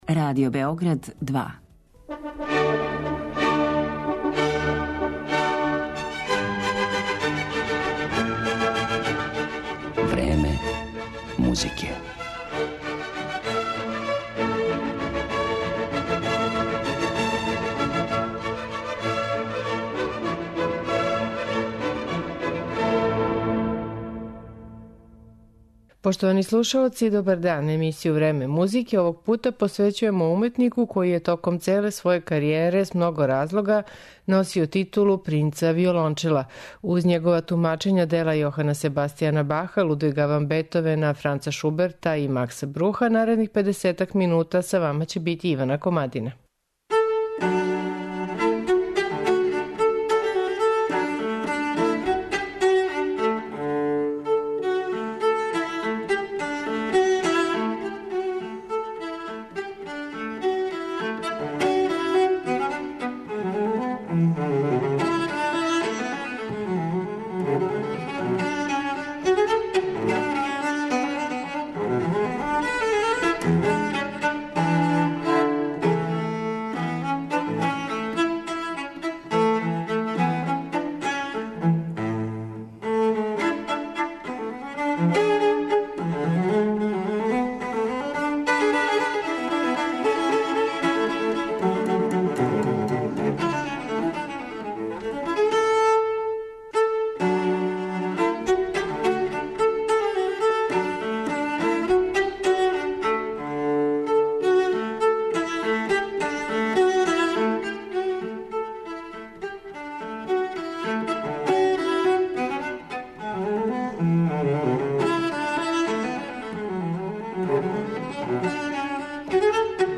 Емисија посвећена виолончелисти Пјеру Фурнијеу
Слушаћете његова тумачења дела Јохана Себастијана Баха, Лудвига ван Бетовена, Франца Шуберта и Макса Бруха.